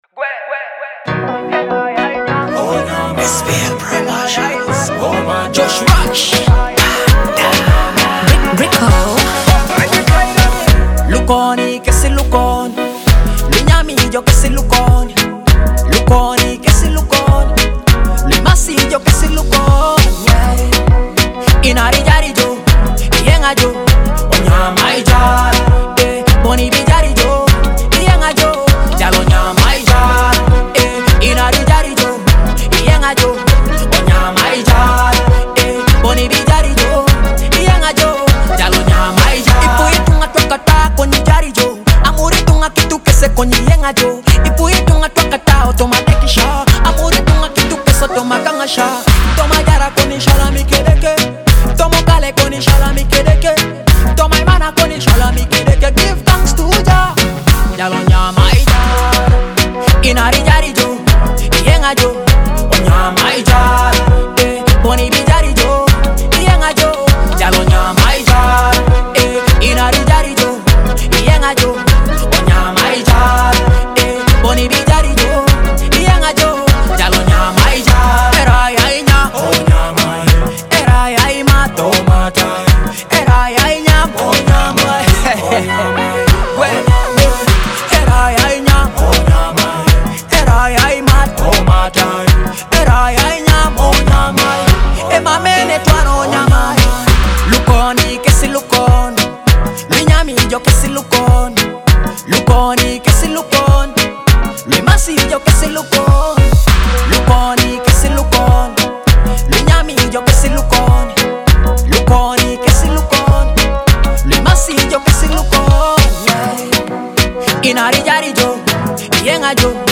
is an upbeat and vibrant Ugandan dancehall anthem
a powerful blend of dancehall and Afrobeat influences